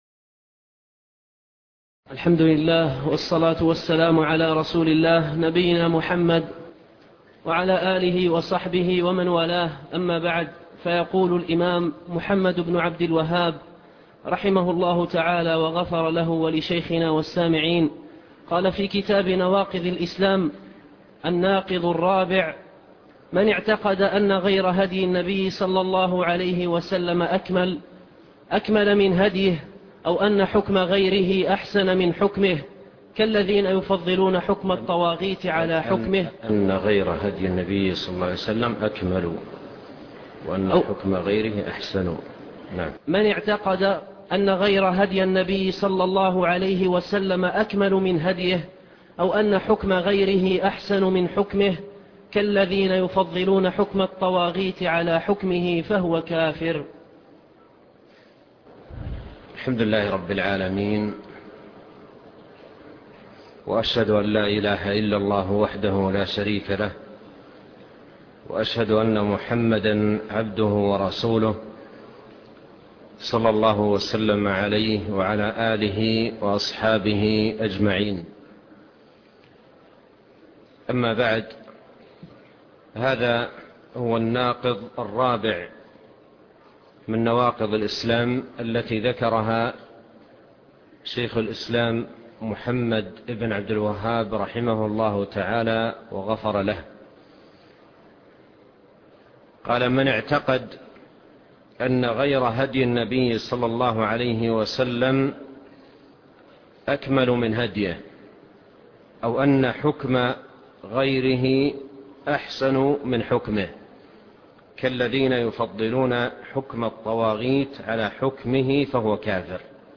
شرح نواقض الإسلام الدرس السادس